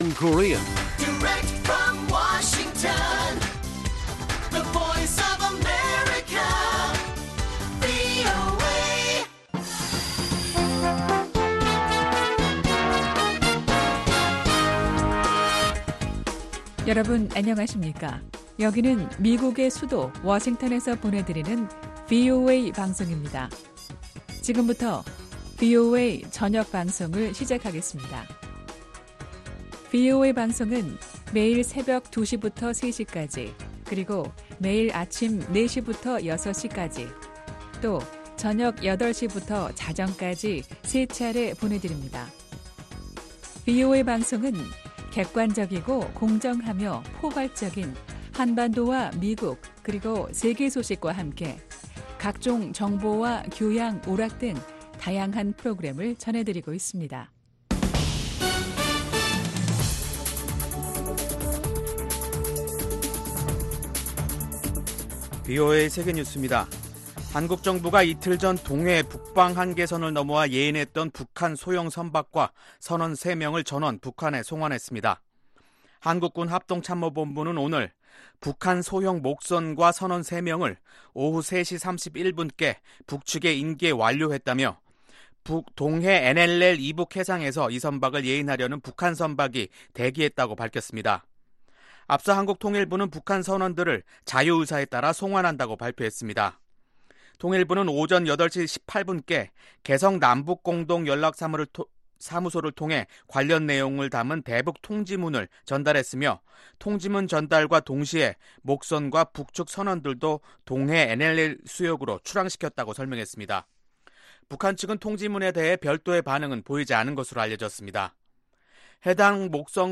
VOA 한국어 간판 뉴스 프로그램 '뉴스 투데이', 2019년 7월 29일 1부 방송입니다. 북한은 미국과의 접촉에 진전이 없는데 대한 불만을 한국에 전가하고 있다고 전문가들이 분석하고 있습니다. 미국은 북한의 최근 탄도미사일 발사에 대해 의미를 축소하고 있으나 추가 도발에 대한 경고를 통해 북한이 ‘레드라인’을 넘을 가능성을 경계하고 있습니다.